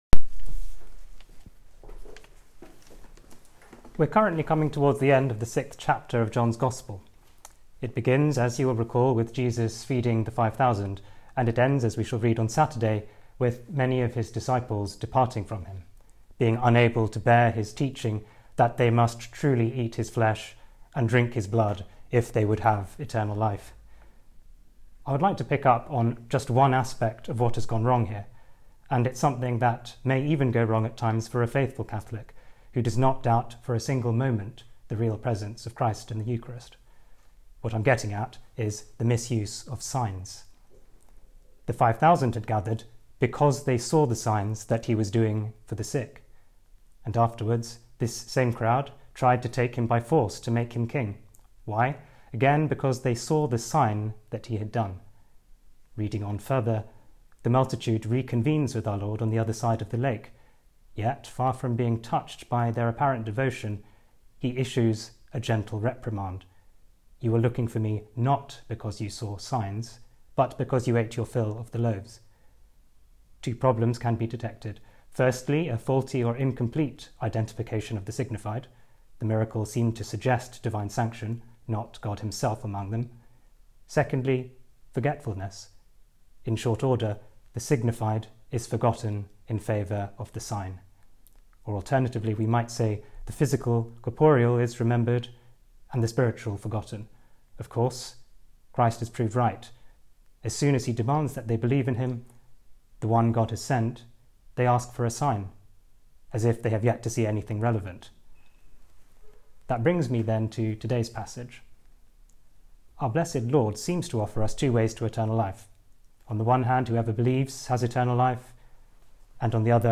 This homily was preached to the student brothers at compline.